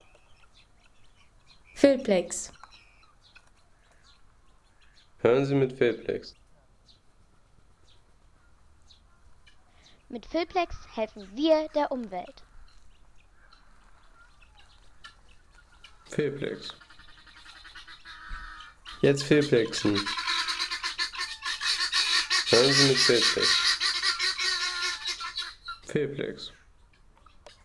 Helmperlhuhn Gackern